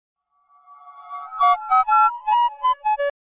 Double Goofy Ahh Android Notification